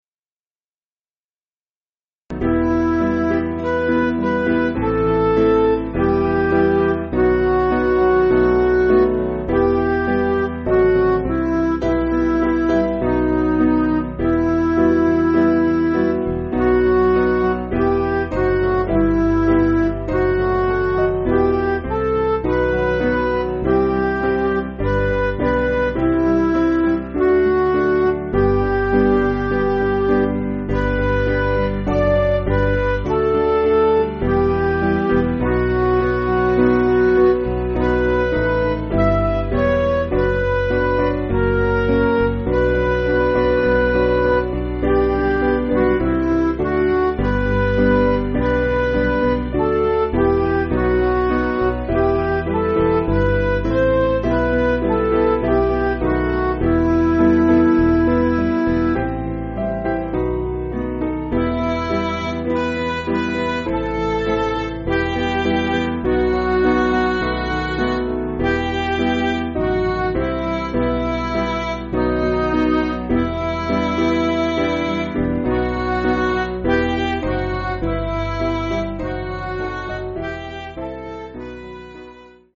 Piano & Instrumental
(CM)   4/Em